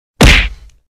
Tiếng Bốp (cú đấm)
Thể loại: Đánh nhau, vũ khí
tieng-bop-cu-dam-www_tiengdong_com.mp3